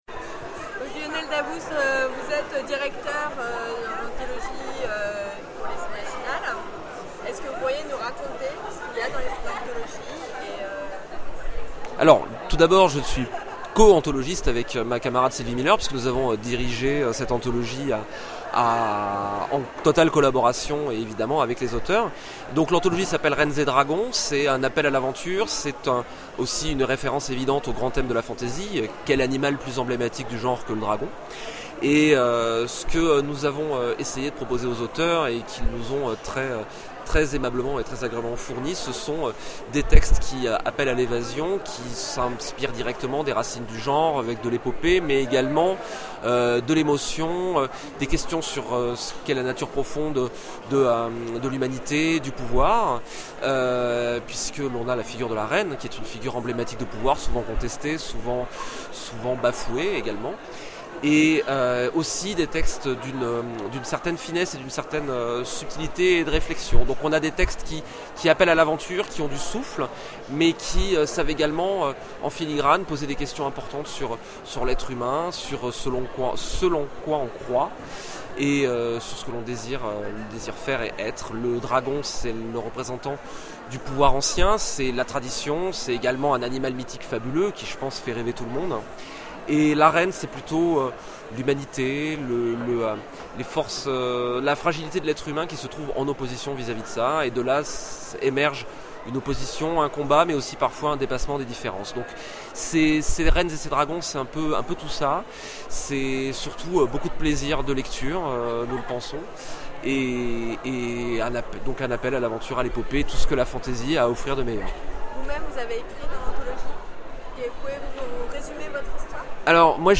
Imaginales 2012 : Interview